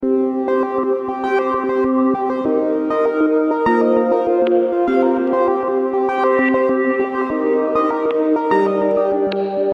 Atmosphere-music-loop.mp3